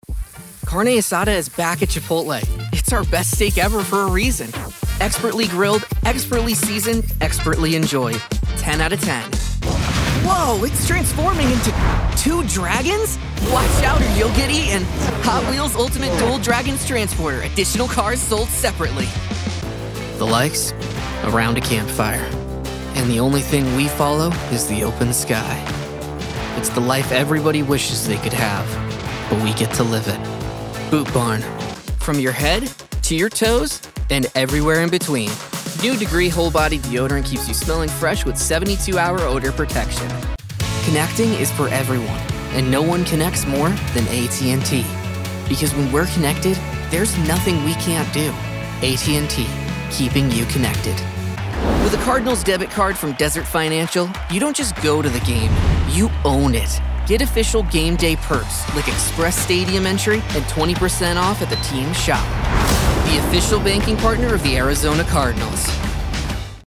Child, Teenager, Young Adult, Adult Has Own Studio
Location: Scottsdale, AZ, USA Voice Filters: VOICEOVER GENRE ANIMATION 🎬 COMMERCIAL 💸